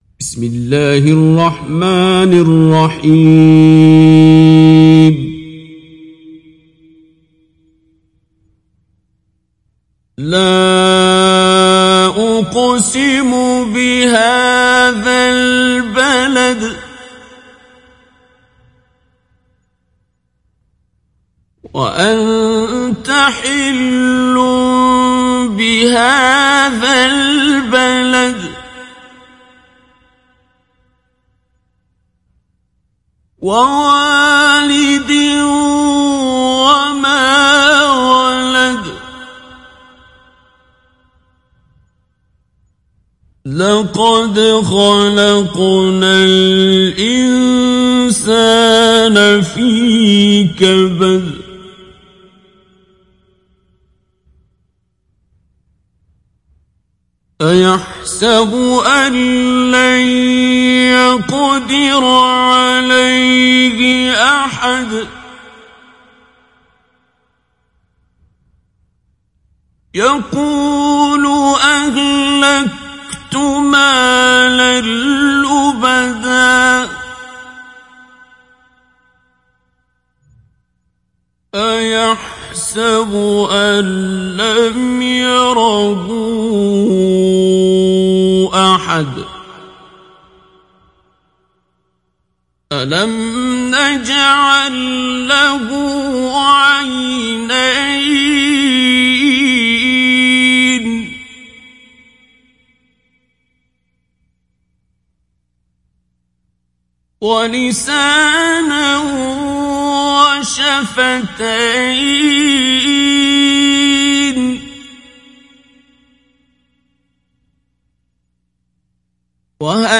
Download Surat Al Balad Abdul Basit Abd Alsamad Mujawwad